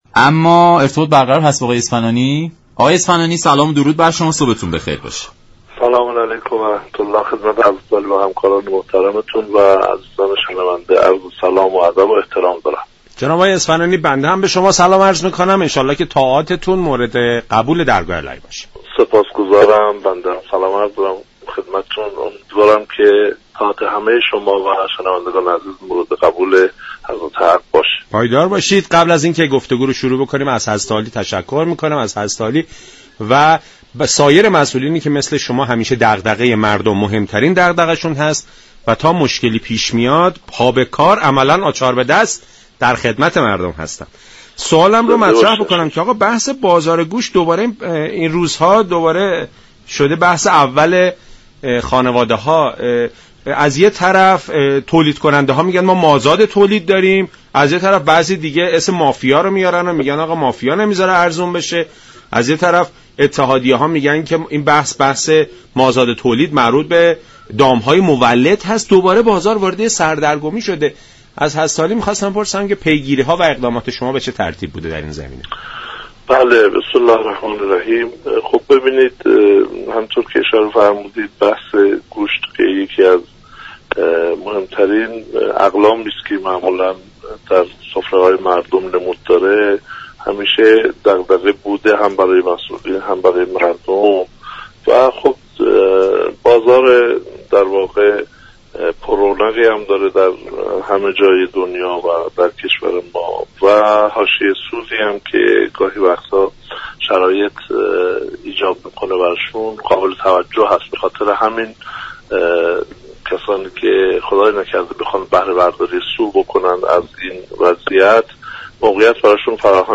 به گزارش شبكه رادیویی ایران، محمد علی اسفنانی سرپرست معاونت اجتماعی سازمان تعزیرات حكومتی در برنامه «سلام صبح بخیر» به گرانی گوشت قرمز اشاره كرد و گفت: بازار گوشت زمینه لازم را برای استفاده فرصت طلبان دارد سازمان تعزیزات حكومتی با افزایش نیروهای خود در بخش های نظارتی تلاش می كند نظارت دقیق تری را بر روند بازار داشته باشد.